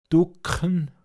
Pinzgauer Mundart Lexikon